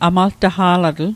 Pronunciation Guide: a·mal·ta·hgaa·la·dêl